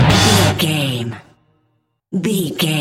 Aeolian/Minor
A♭
Fast
drums
hard rock
lead guitar
bass
aggressive
energetic
intense
nu metal
alternative metal